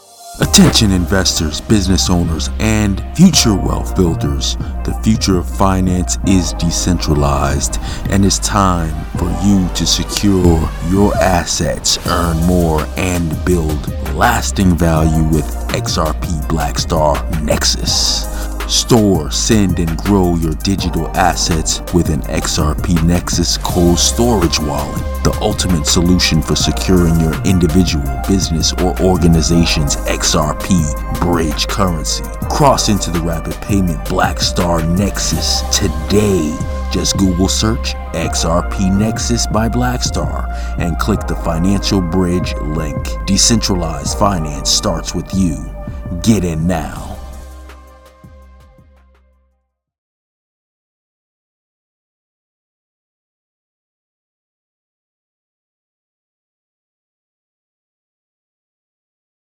1 Minute Radio Commercial